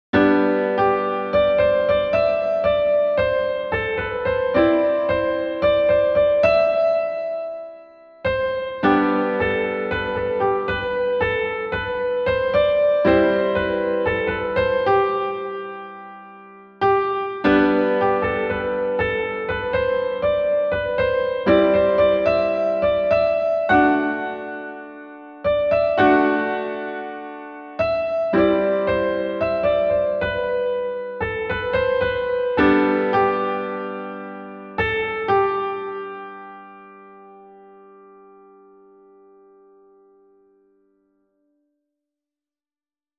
IIVV Chords
Improv-I-IV-V.mp3